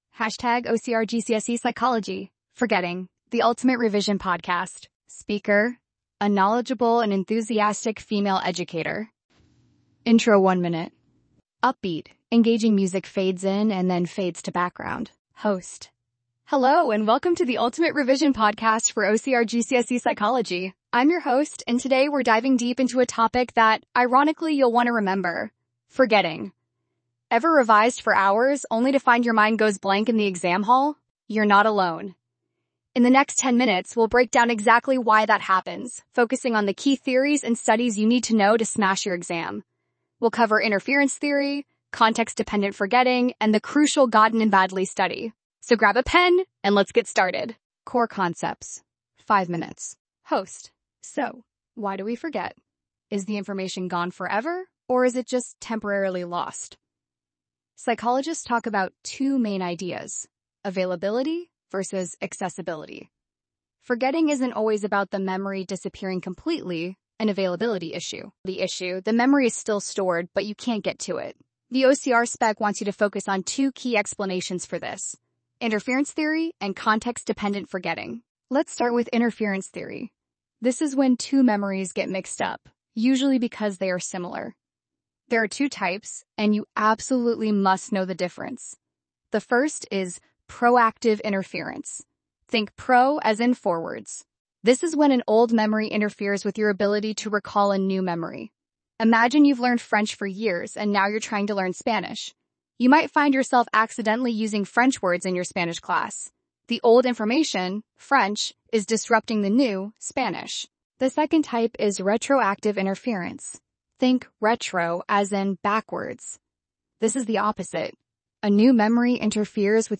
Speaker: A knowledgeable and enthusiastic female educator.